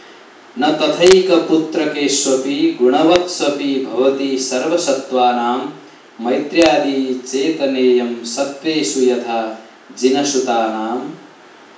āryā